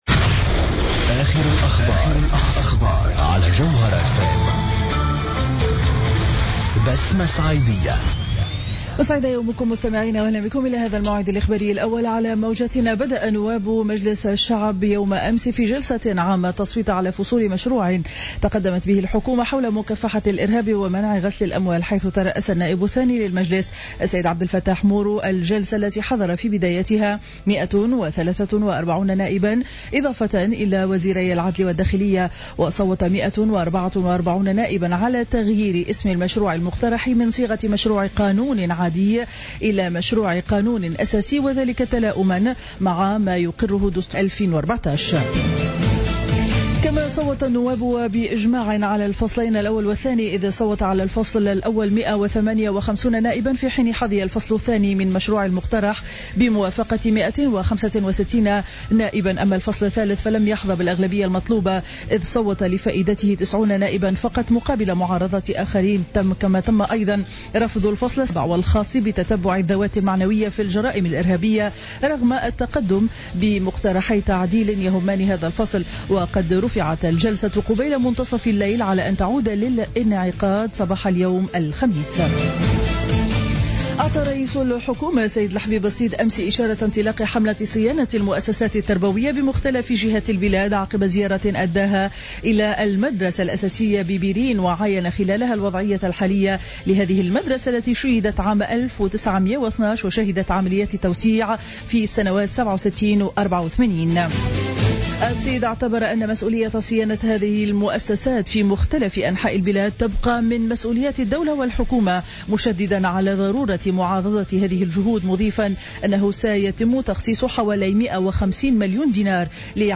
نشرة أخبار السابعة صباحا ليوم الخميس 23 جويلية 2015